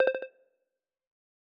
cyber_line.wav